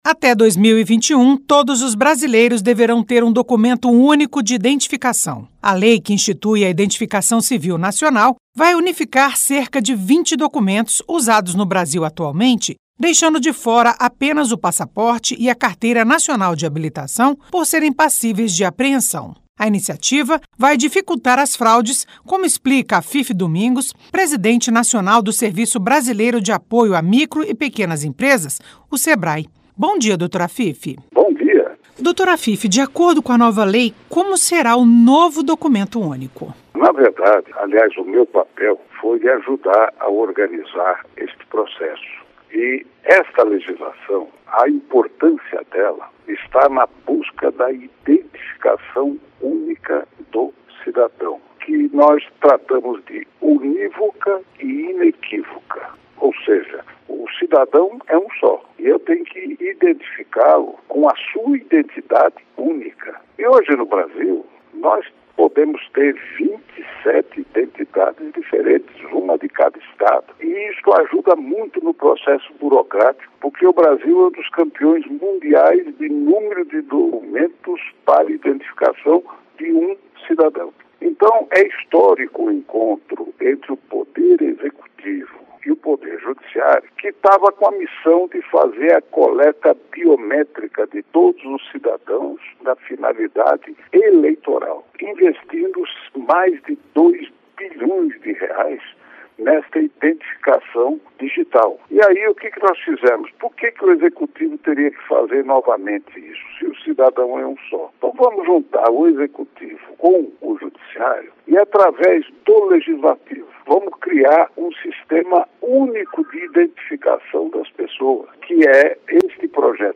Entrevista com o presidente do Serviço Brasileiro de Apoio às Micro e Pequenas Empresas (Sebrae), Afif Domingos.